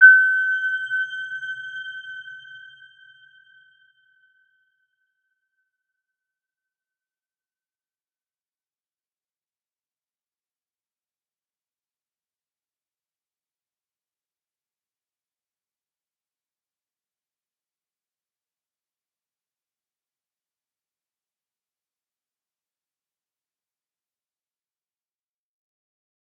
Round-Bell-G6-f.wav